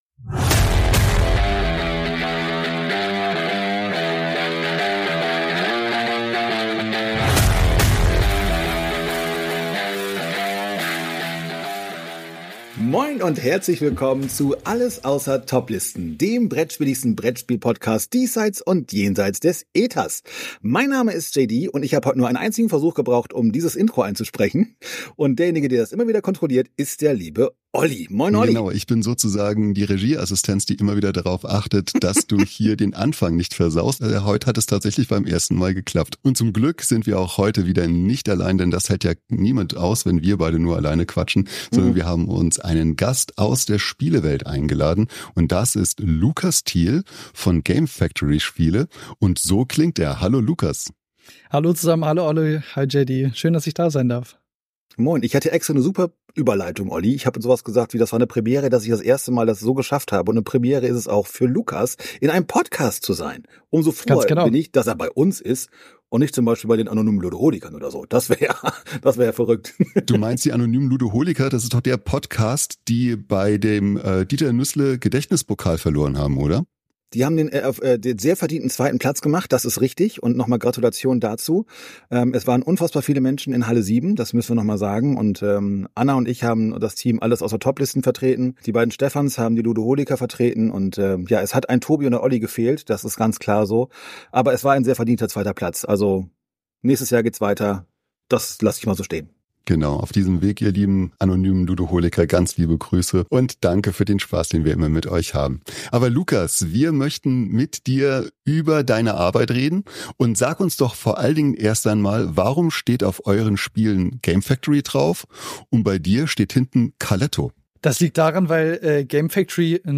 Interviewfolge